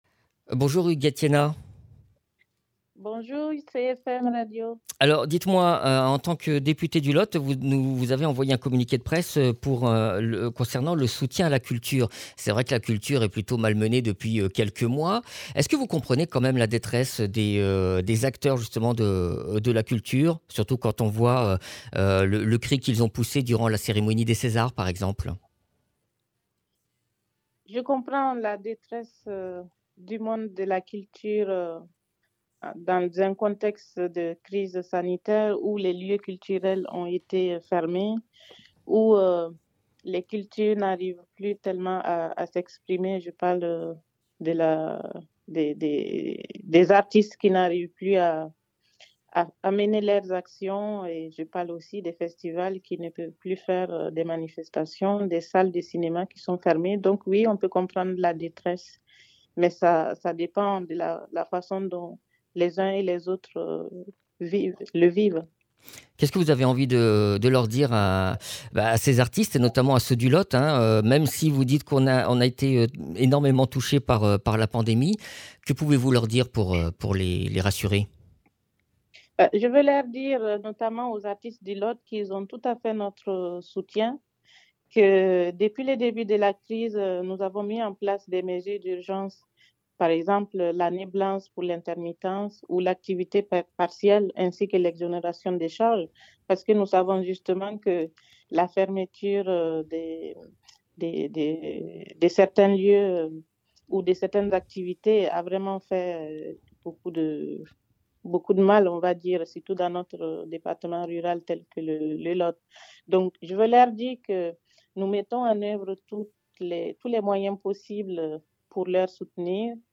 Interviews
Invité(s) : Huguette Tiegna, députée LaREM du Lot